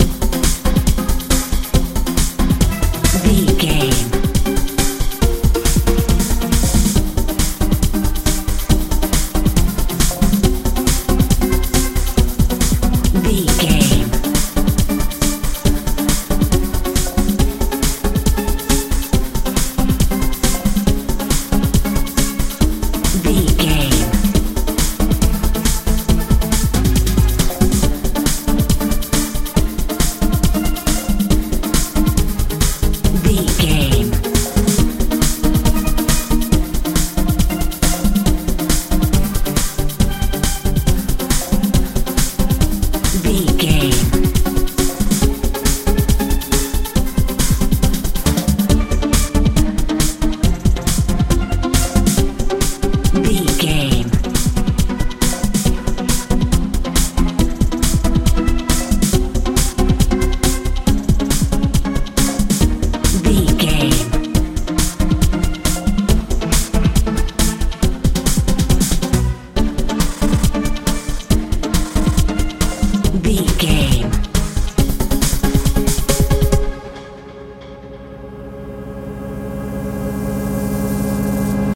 modern dance
Ionian/Major
fun
playful
synthesiser
bass guitar
drums
80s
90s